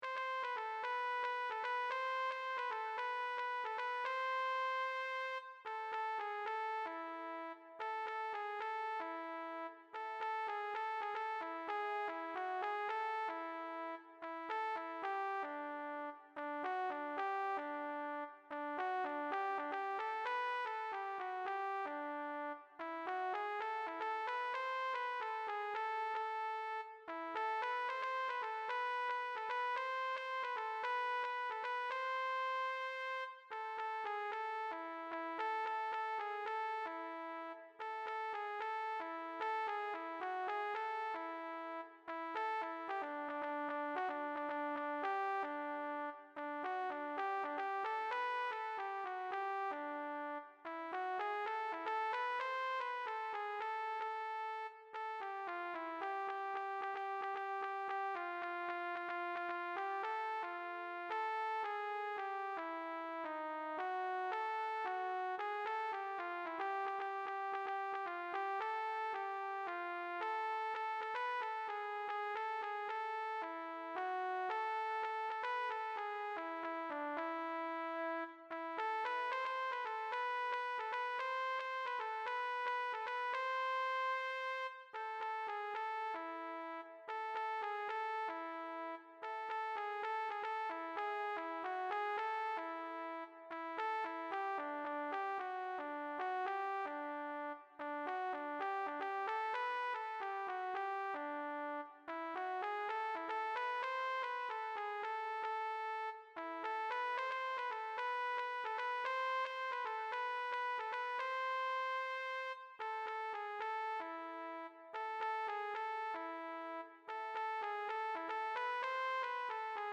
MIDI recording of the MEZZOSOPRANO voice.
for female chorus in 3, SMA
MIDI audio for the Mezzo voice.
for SMA chorus